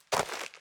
footstep_dirt_road.ogg